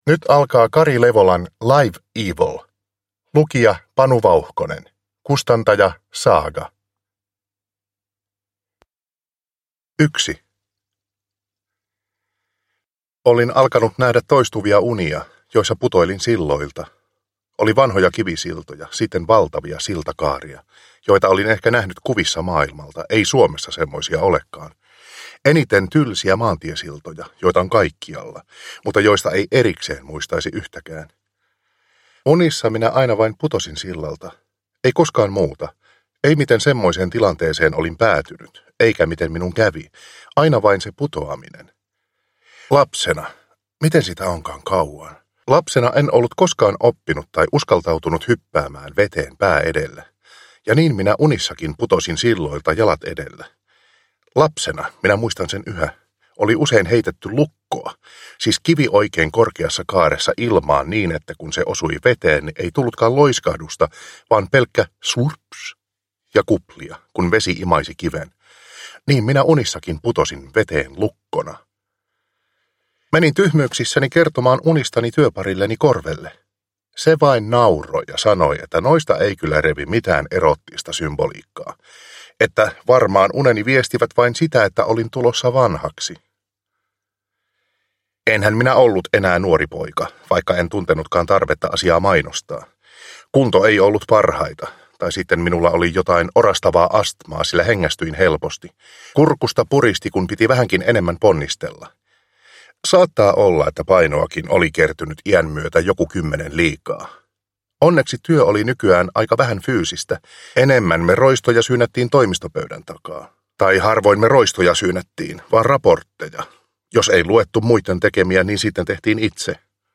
Live Evil / Ljudbok